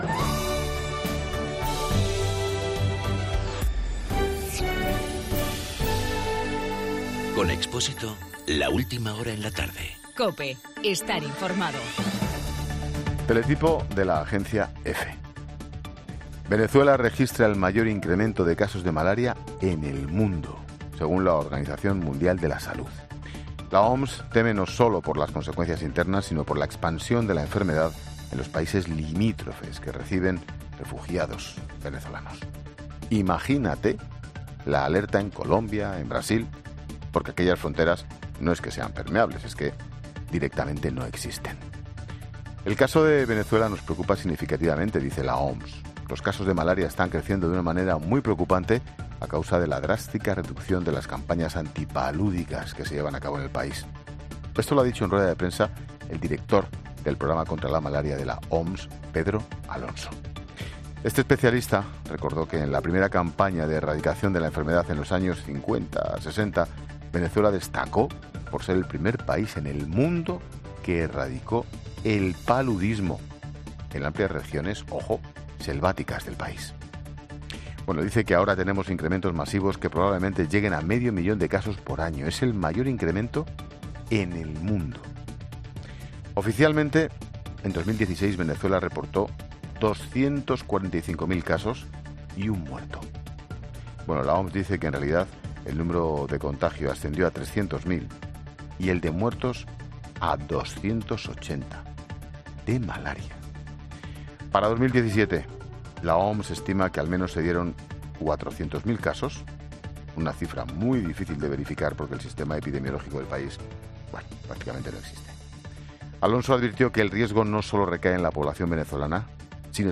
Monólogo de Expósito
El comentario de Ángel Expósito por los datos de la OMS sobre Venezuela con el mayor incremento de casos de malaria en el mundo.